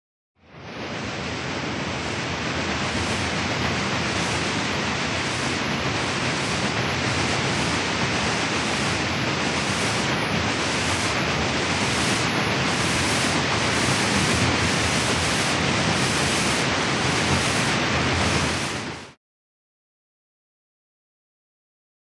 Шум гребного колеса парохода